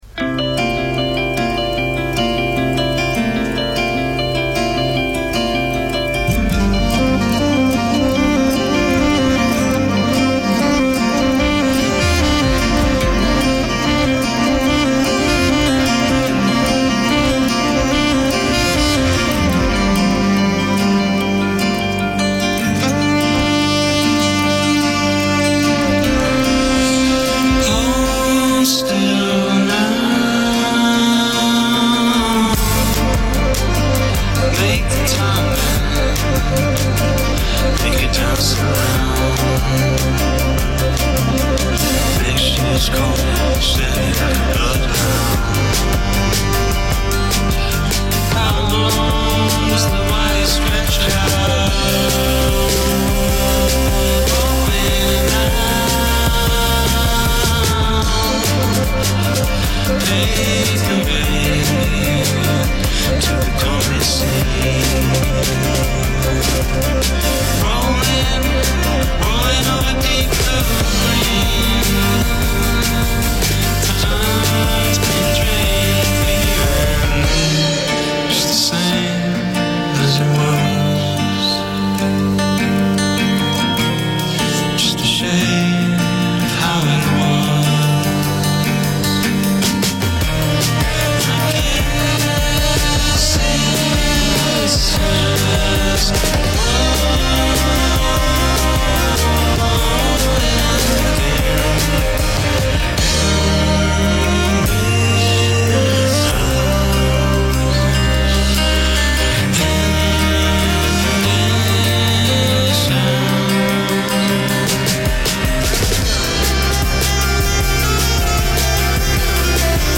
in session
co-frontmen and guitarists
bassist
saxophonist
drummer